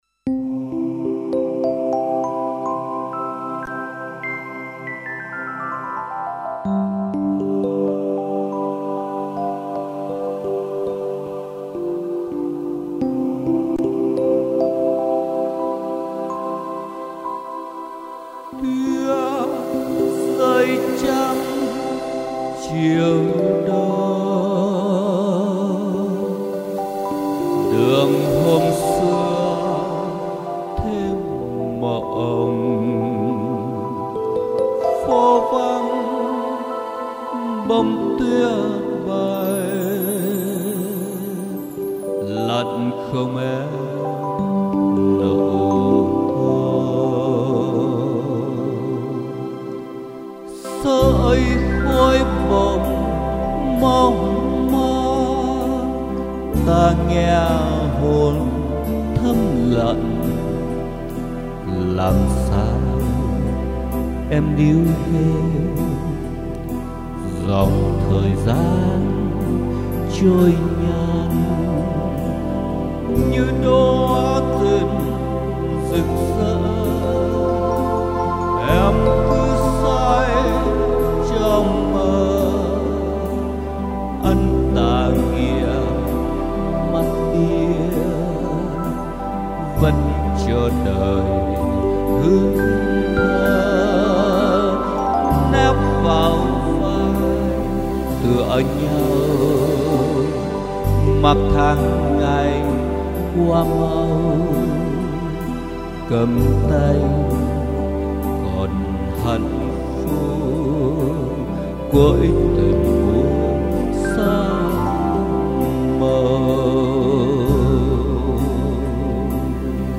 Lời và tiếng hát